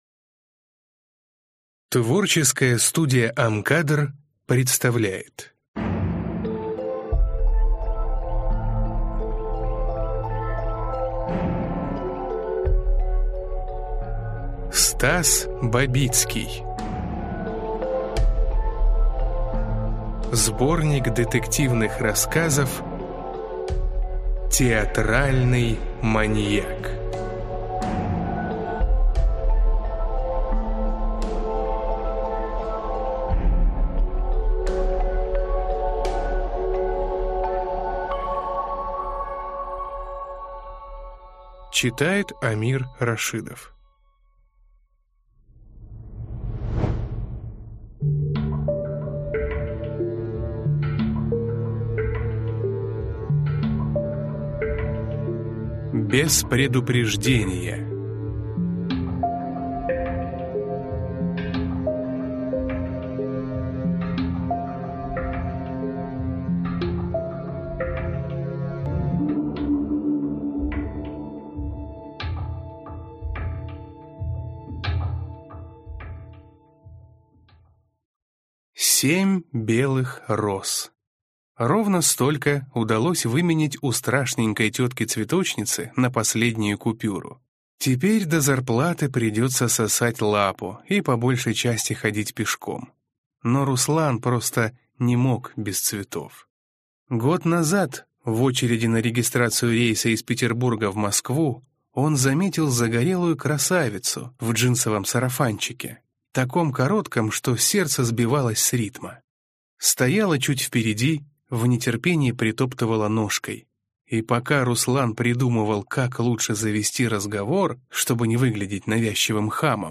Аудиокнига Театральный маньяк | Библиотека аудиокниг